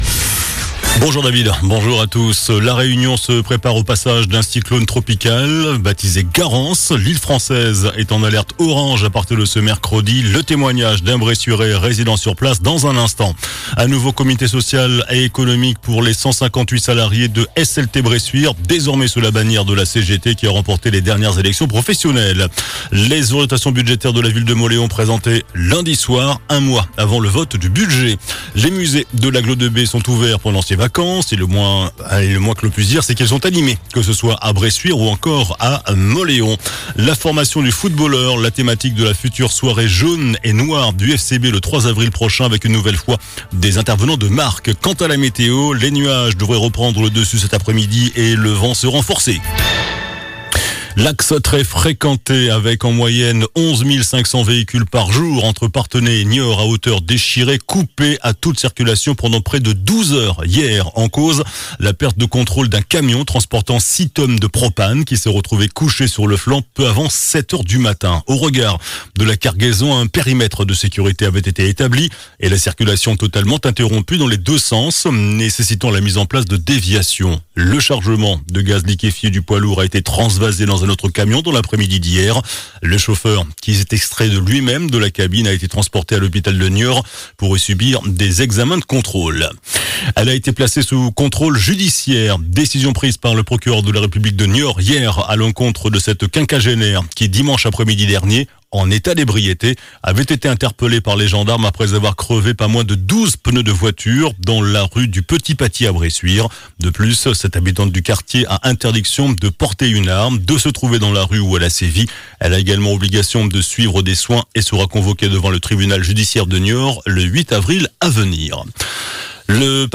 JOURNAL DU MERCREDI 26 FEVRIER ( MIDI )